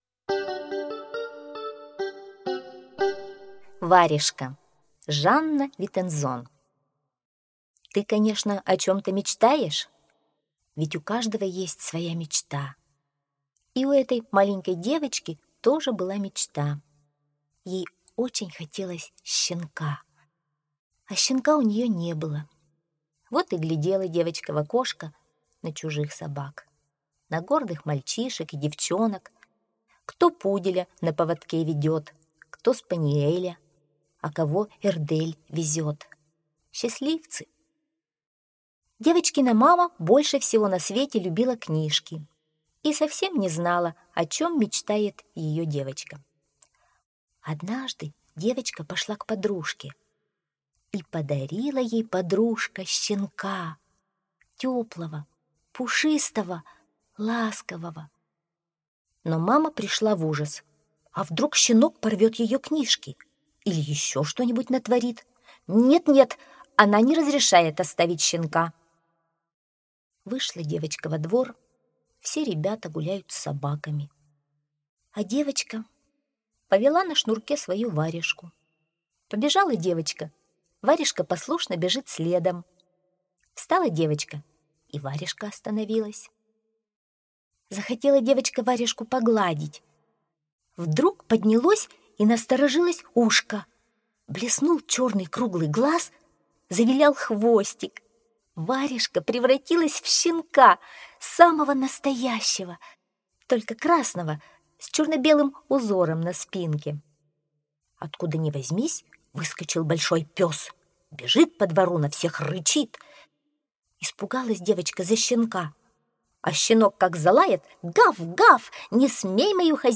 Варежка - аудиосказка Витензон - слушать онлайн